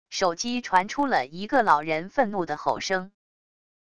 手机传出了一个老人愤怒的吼声wav音频